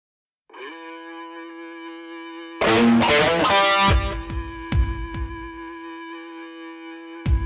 Straight und dynamisch
mit coolem Groove